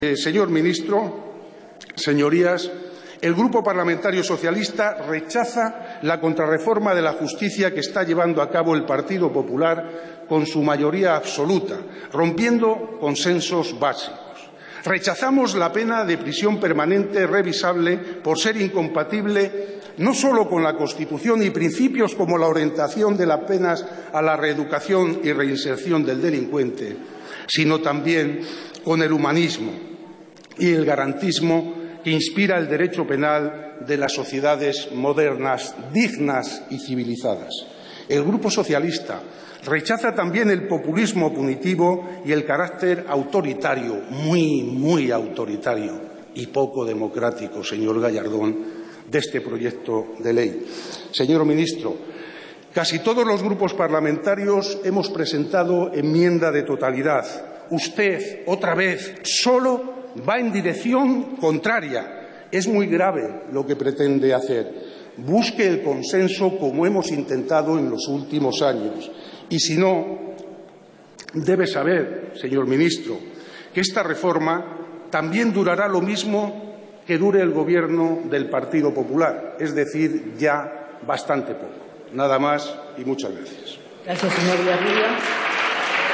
Fragmento de la intervención de Julio Villarrubia en el pleno en el que se vota la reforma del Código Penal del Ministro Gallardón 12/12/2013